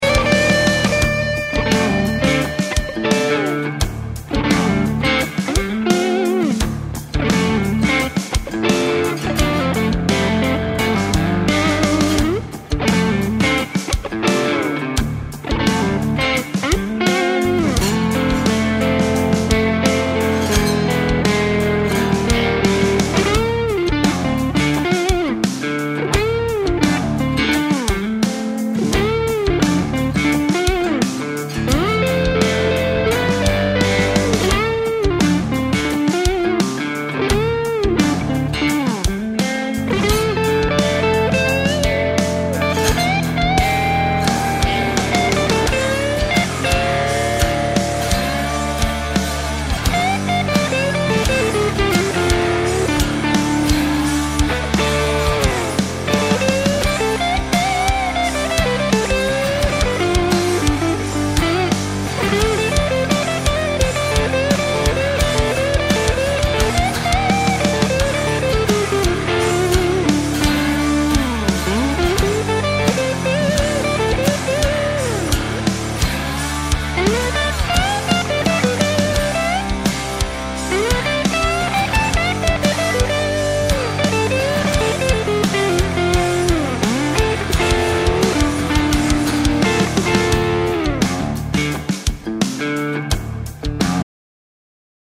Zu Erwähnen ist noch überhaupt der Einstieg ins Solo bzw. wieder der Wechsel zum 1. Teil.